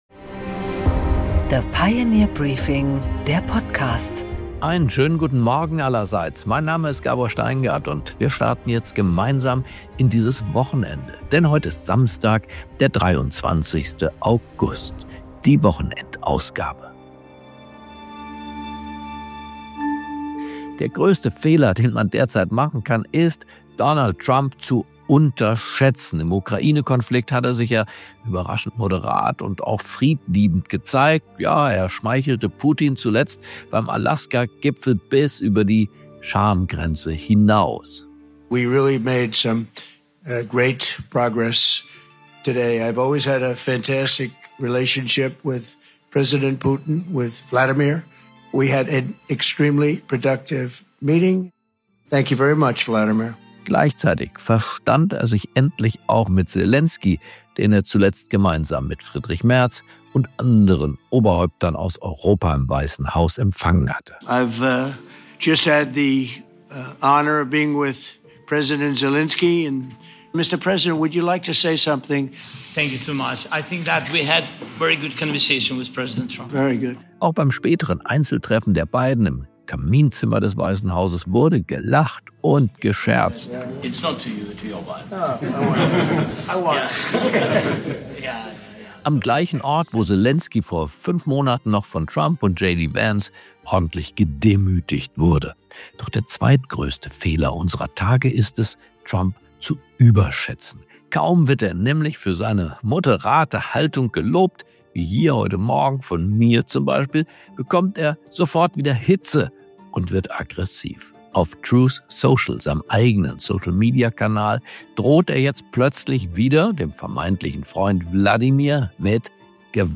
Gabor Steingart präsentiert die Pioneer Briefing Weekend Edition.